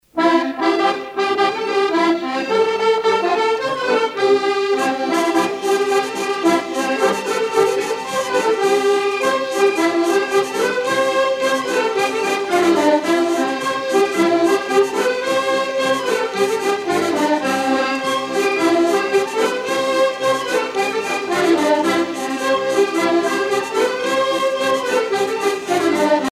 danse : polka piquée
Pièce musicale éditée